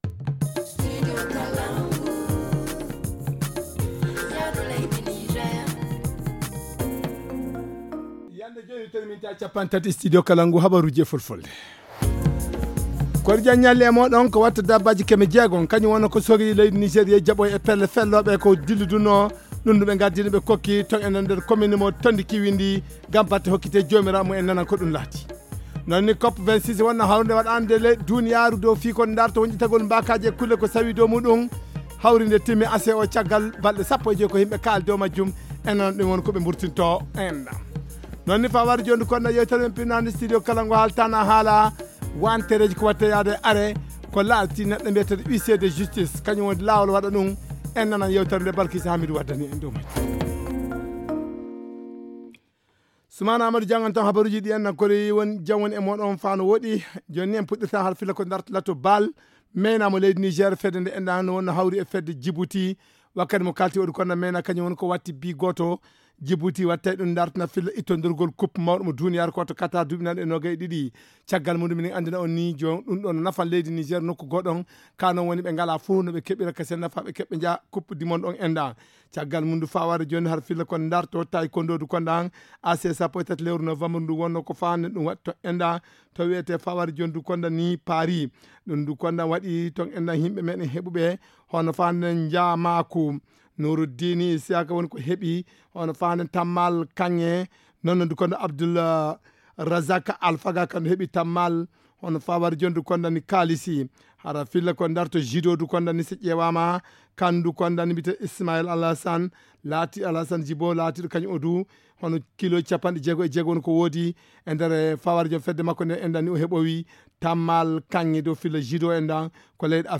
Le journal du 15 novembre 2021 - Studio Kalangou - Au rythme du Niger